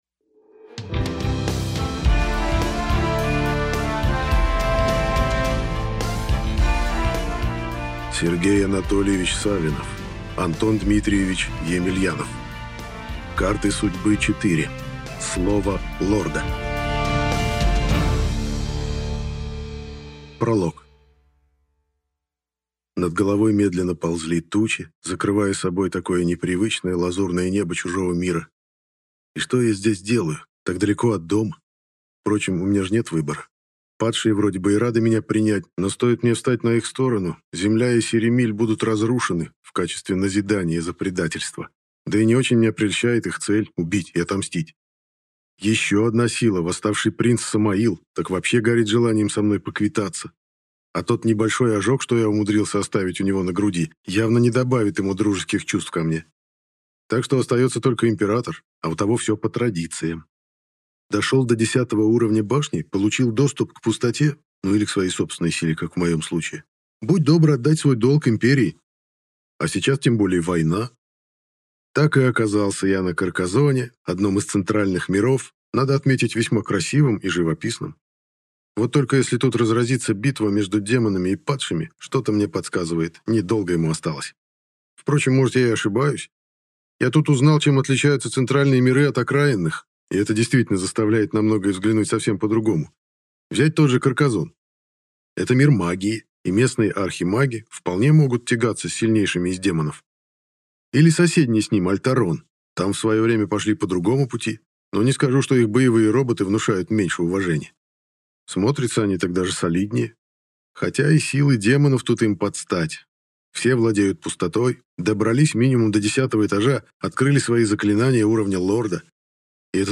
Аудиокнига Карты судьбы 4. Слово лорда | Библиотека аудиокниг